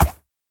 mob / horse / soft3.ogg